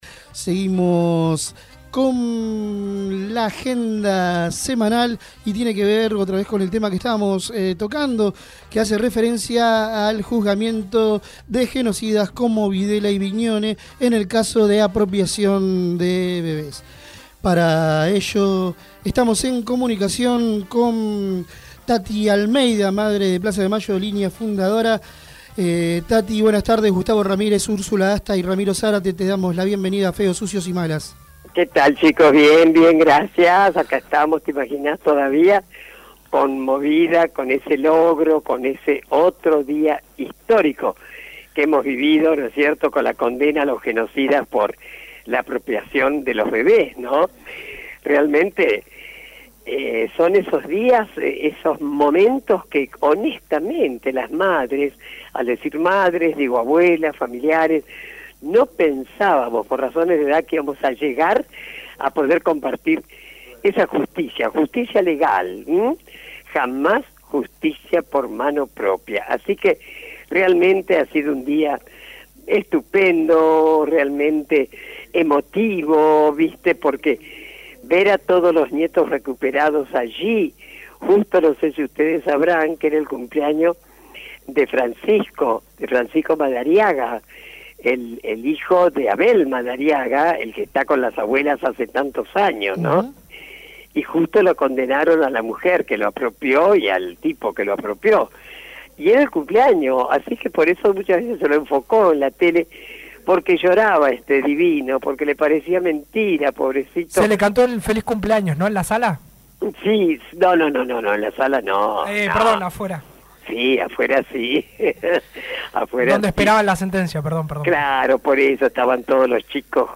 Tati Almeida, referente de Madres de Plaza de Mayo Línea Fundadora, habló en Feos, Sucios y Malas (Sábados de 18 a 20hs).